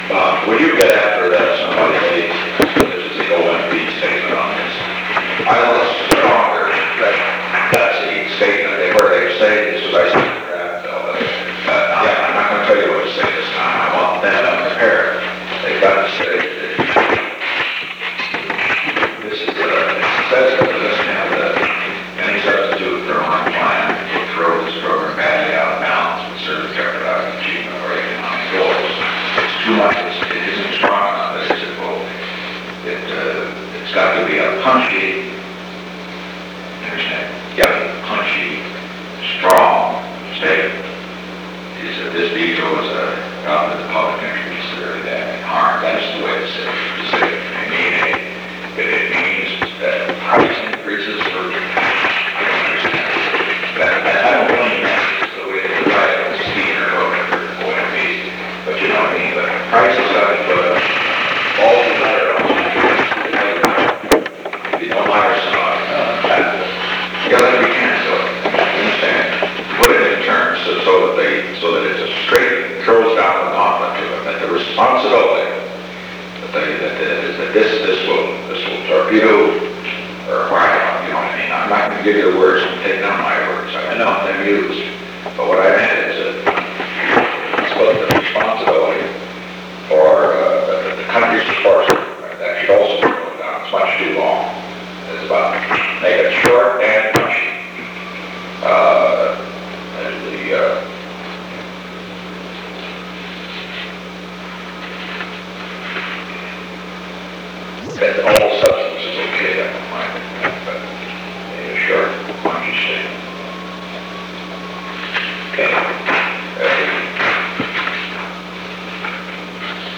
Secret White House Tapes
Conversation No. 582-2
Location: Oval Office
The President met with H. R. (“Bob”) Haldeman.